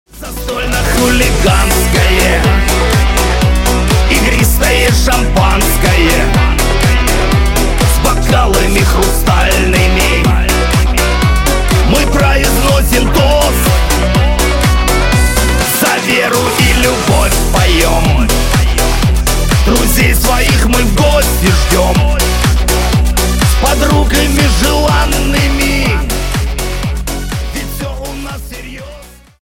Шансон
Скачать припев песни